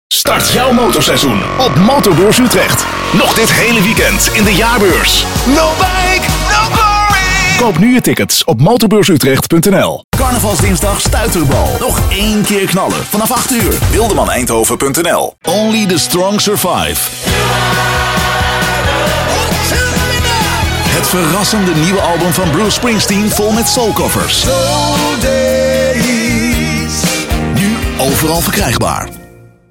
stemdemo